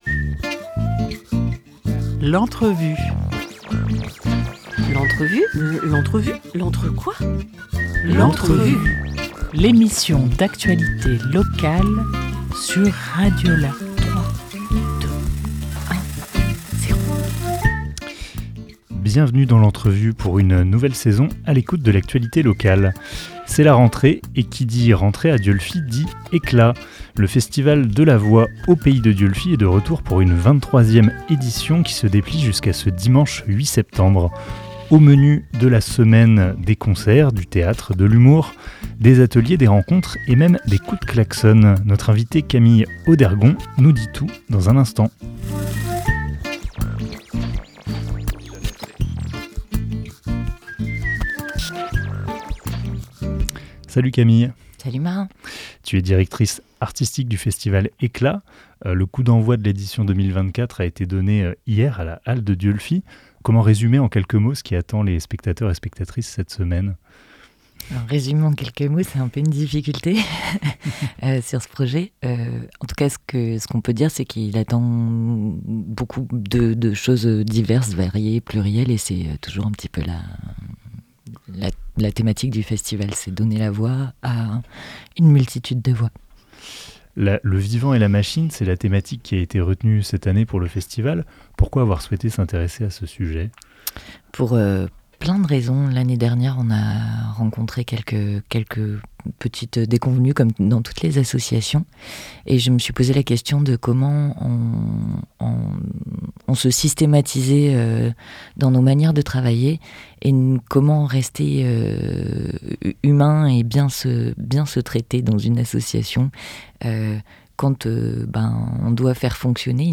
3 septembre 2024 10:52 | Interview